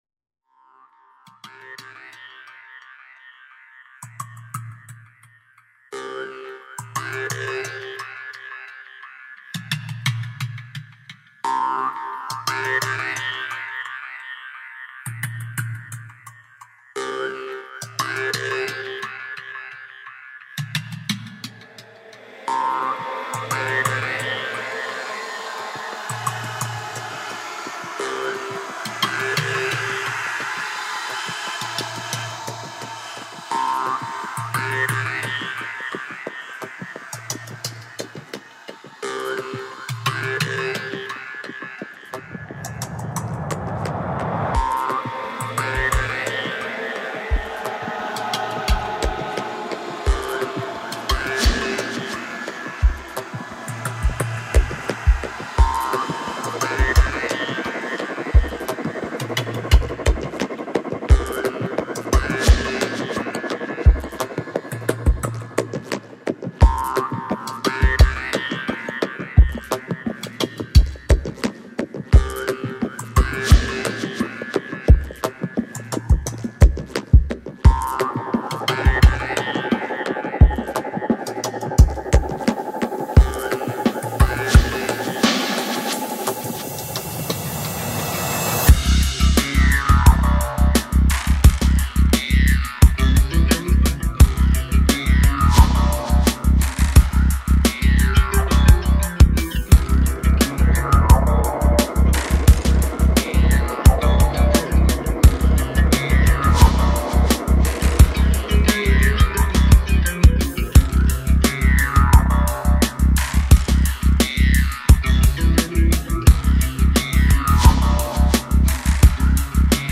"Deep tribal magick.."